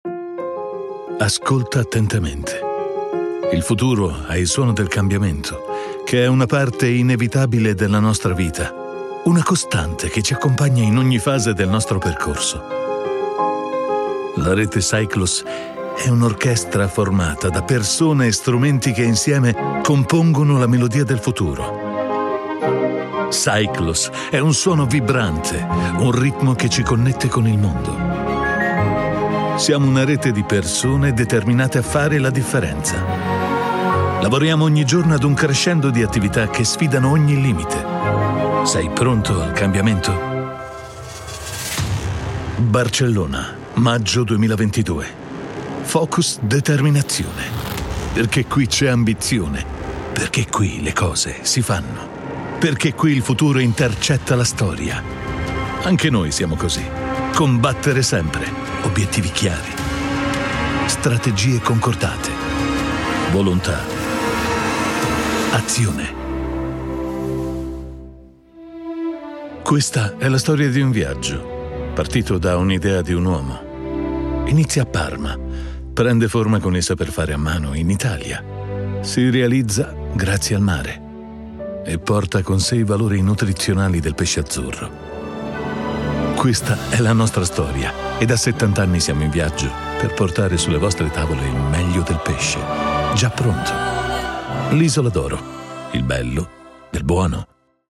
Weltweit bekannte Marken vertrauen ihm und er liefert professionelle Voiceover-Dienste mit Wärme, Klarheit und Schnelligkeit aus seinem hochmodernen Studio.
Unternehmensvideos
UAD Apollo X8, Mac Pro, U87Ai, TLM103, TLM67 und mehr.